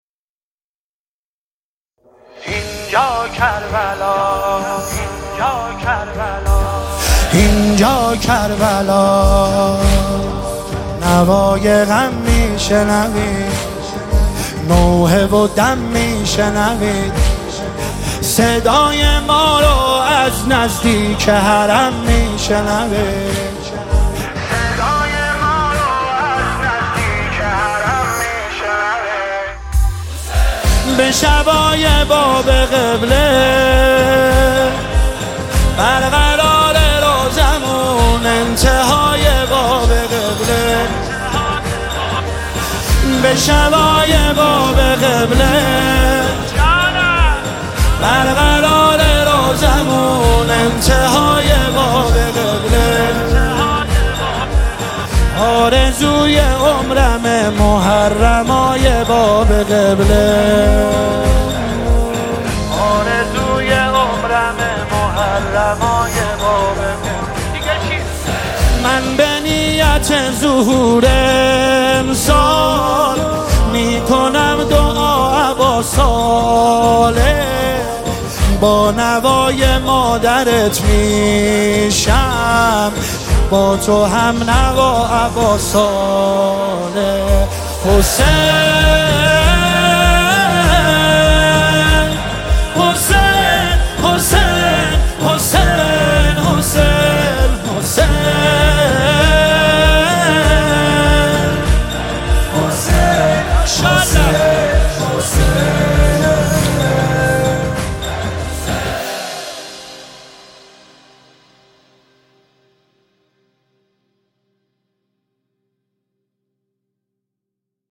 نماهنگ استودیویی